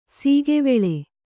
♪ sīgeveḷe